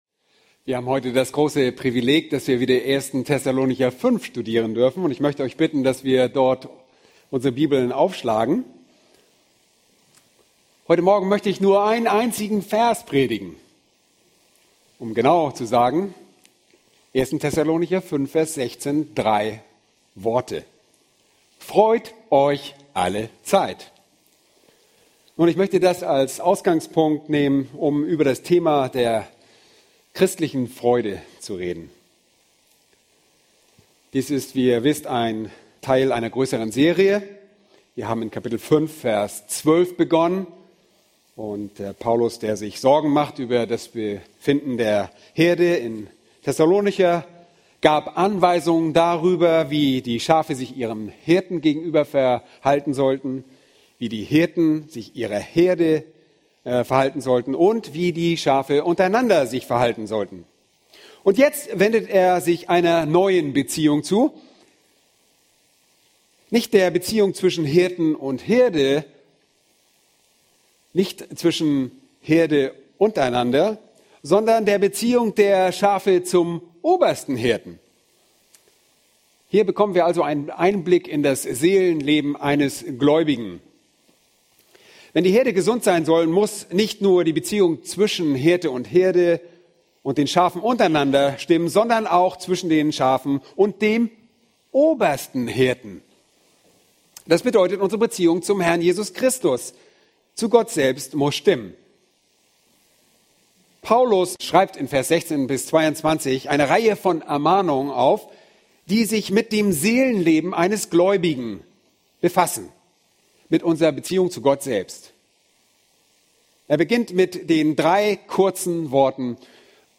Aus der Serie: Die bibelorientierte Gemeinde*